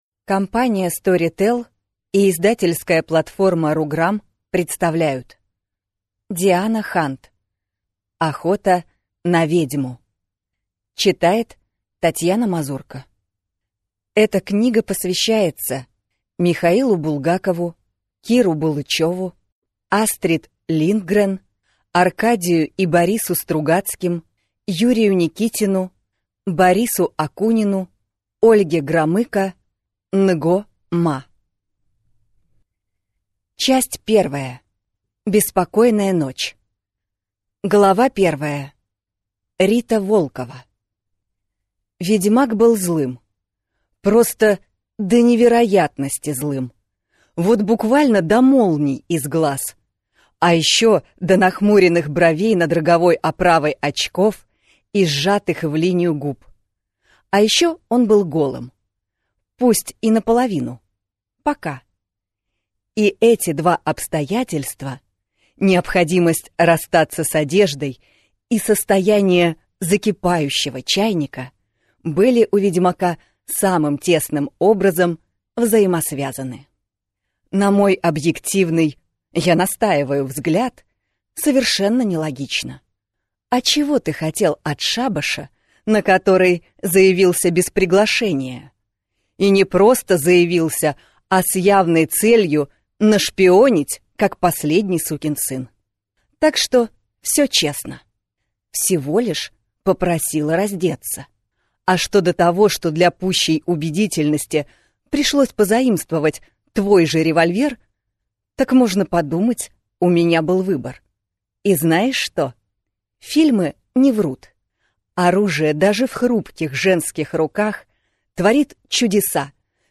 Аудиокнига Охота на ведьму | Библиотека аудиокниг
Прослушать и бесплатно скачать фрагмент аудиокниги